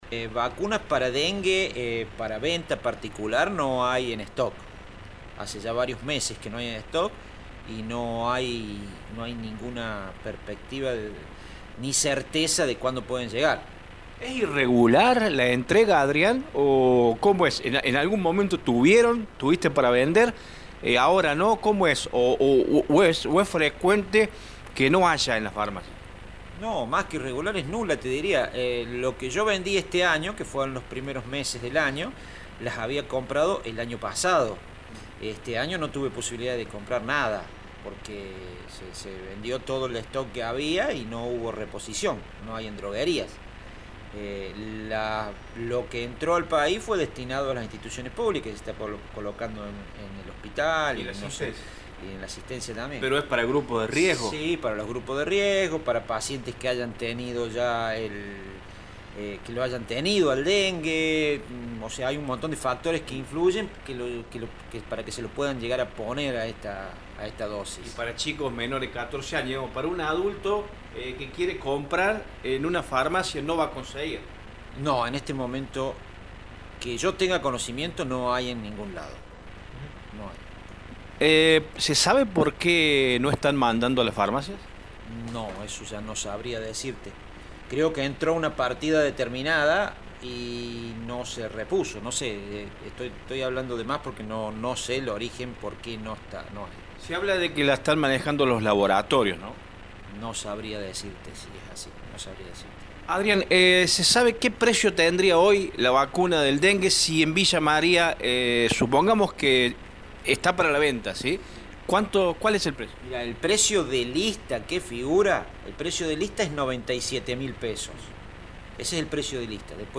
dialogó con Radio Show al respecto